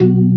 FST HMND B1.wav